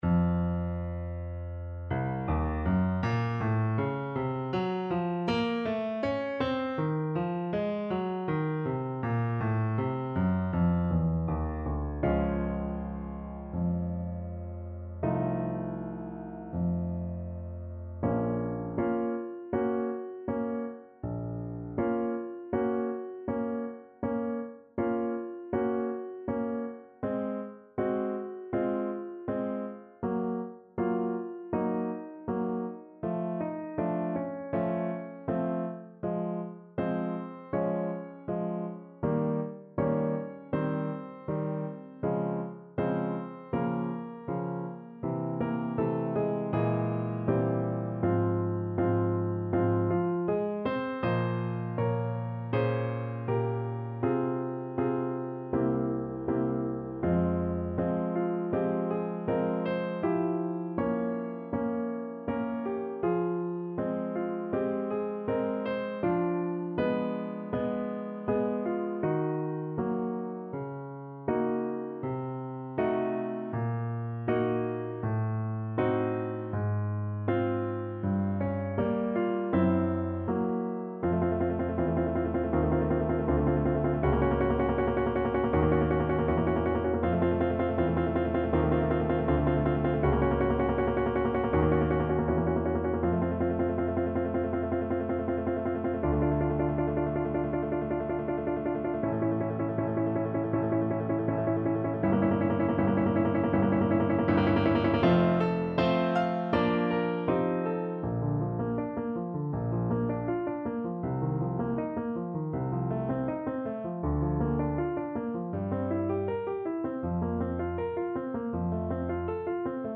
Play (or use space bar on your keyboard) Pause Music Playalong - Piano Accompaniment Playalong Band Accompaniment not yet available transpose reset tempo print settings full screen
Bb minor (Sounding Pitch) (View more Bb minor Music for Trombone )
~ = 100 Molto moderato =80